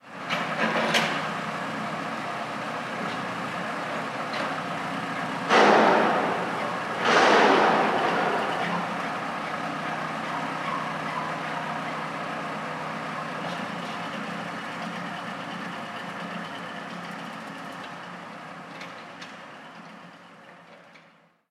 Ambiente obra de construcción
Sonidos: Industria